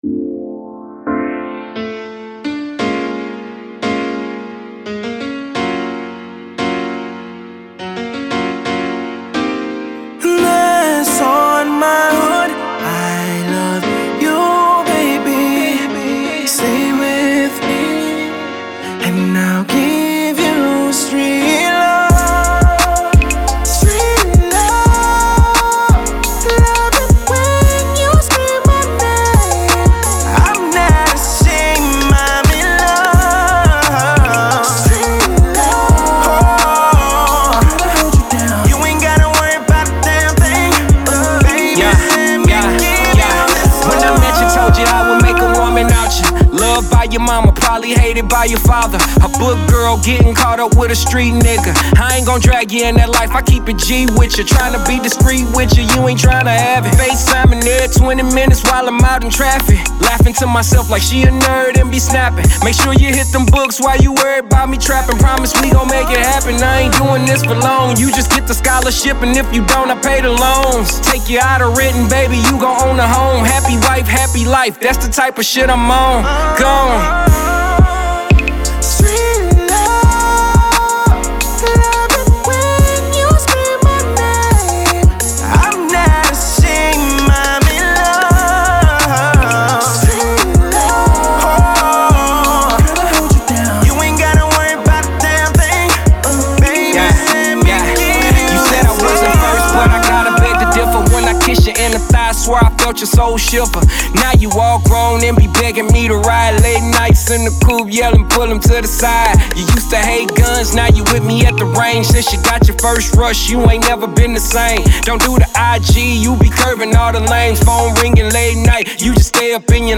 samples an old school track for new single